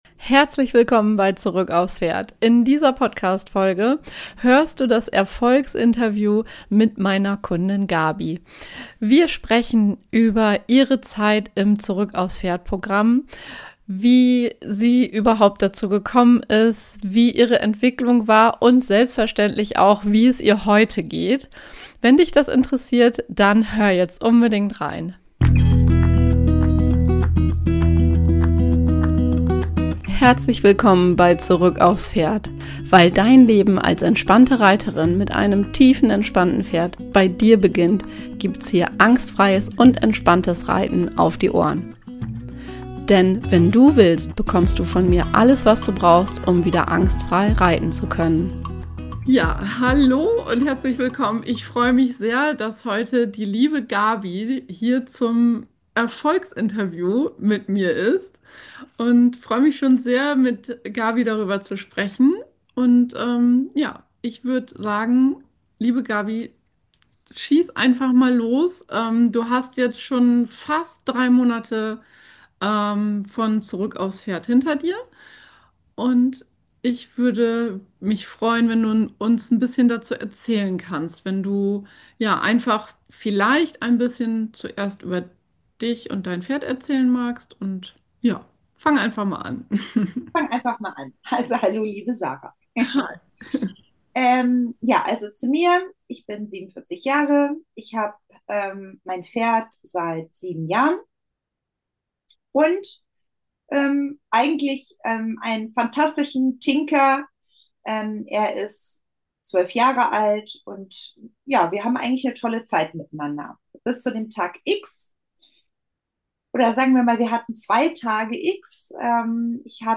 #39 Erfolgsinterview ~ Zurück aufs Pferd Podcast